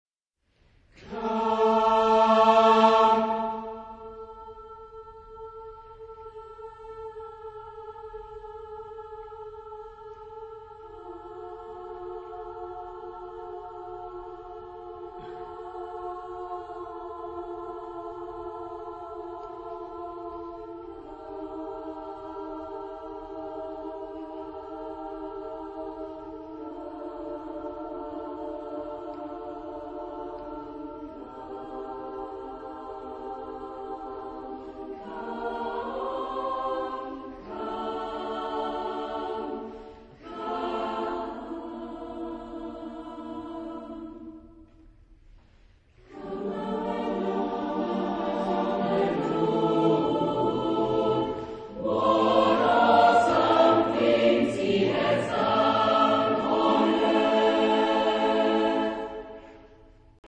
Genre-Style-Form: Contemporary ; Sacred
Type of Choir: SSAATB  (6 mixed voices )
Discographic ref. : 7. Deutscher Chorwettbewerb 2006 Kiel